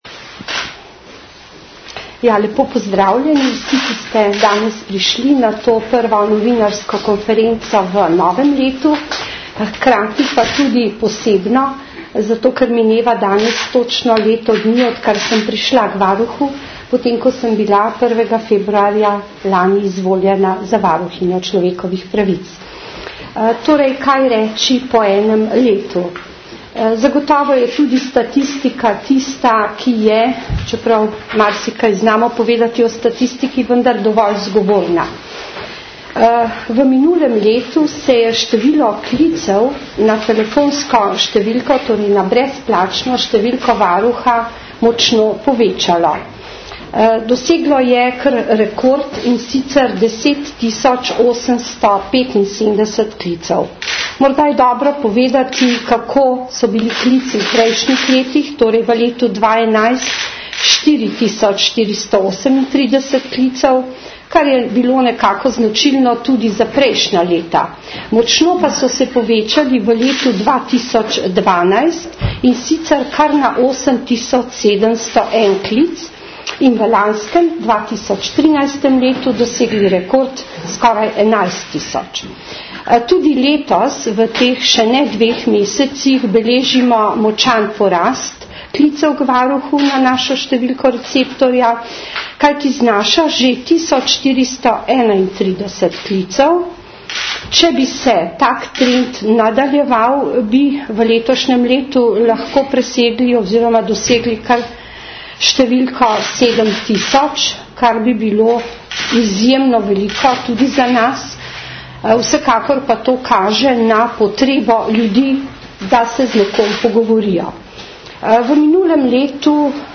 Drugi del novinarske konference: odgovori na vprašanja novinarjev - ZVOČNI POSNETEK (MP3): govorijo varuhinja človekovih pravic Vlasta Nussdorfer, namestnik varuhinje Jernej Rovšek (o arhivih), namestnica varuhinja mag.